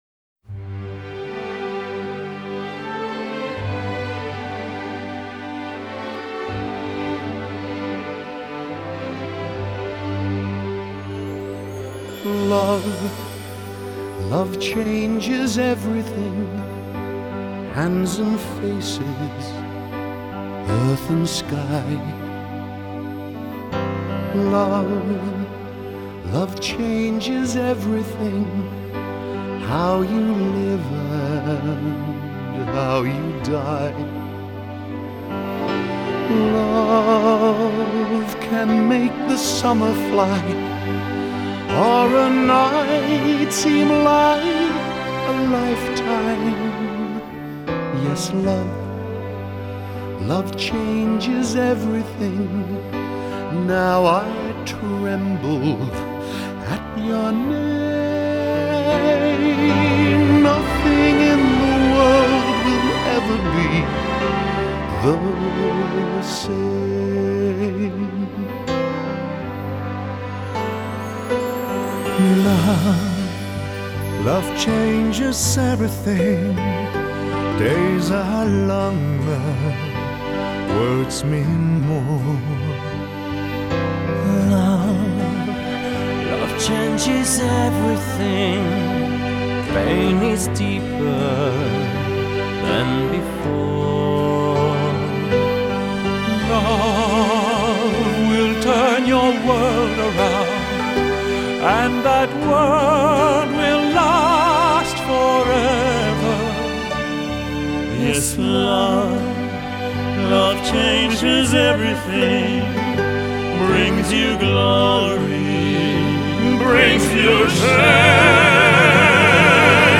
Genre: Pop, Crossover, Musical